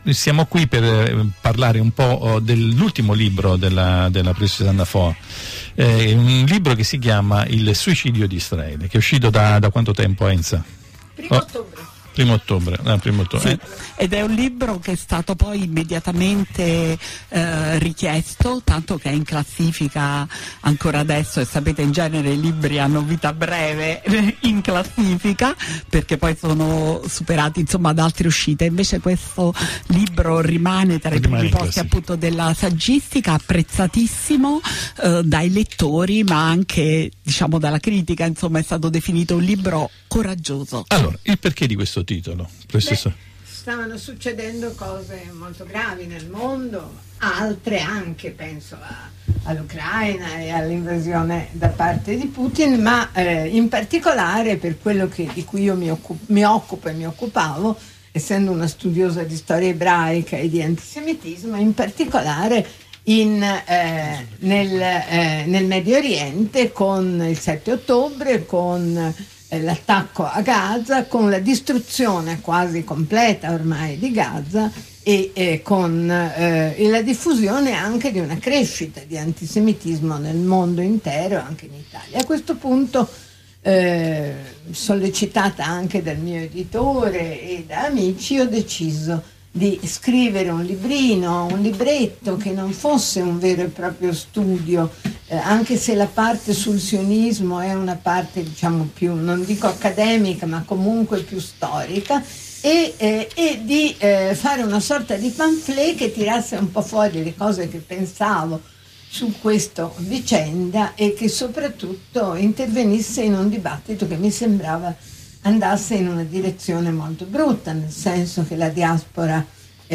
Lunedi 16 dicembre Ospite a Radio Formia nel programma “Di nuovo tutti insieme”, Anna Foa. Si è parlato del suo ultimo libro “Il suicidio di Israele” e di molto altro ancora.